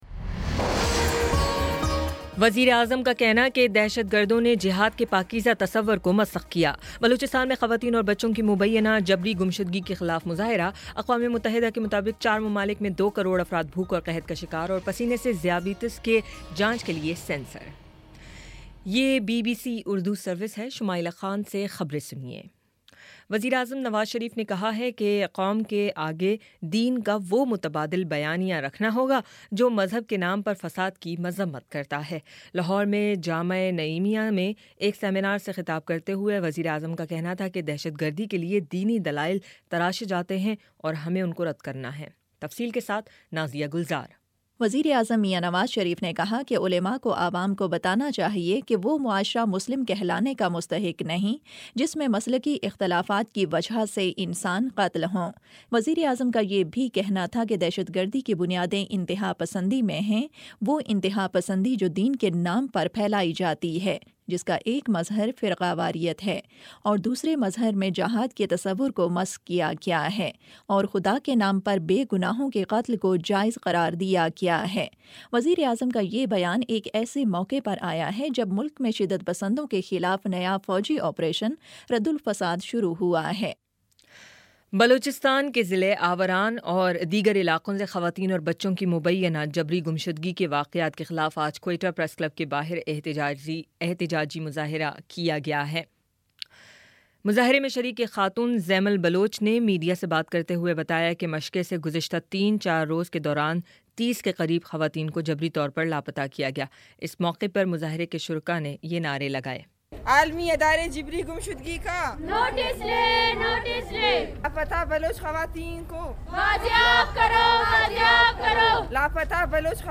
مارچ 11 : شام پانچ بجے کا نیوز بُلیٹن